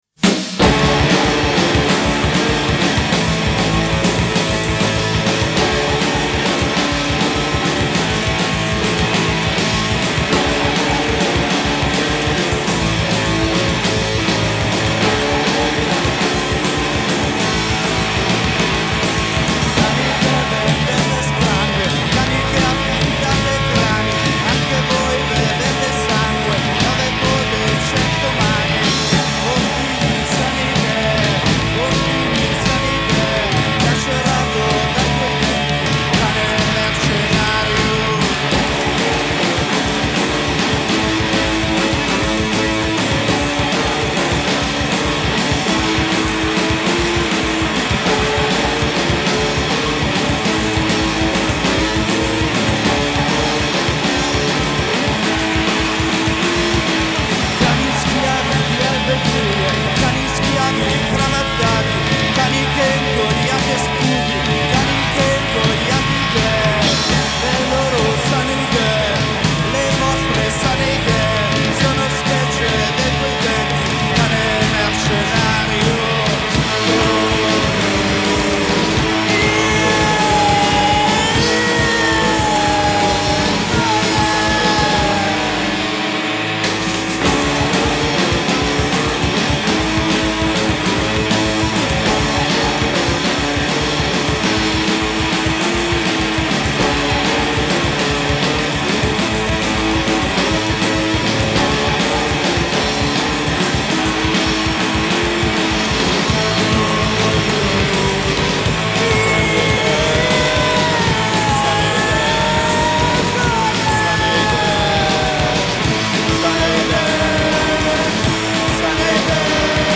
alla chitarra
al basso
alla batteria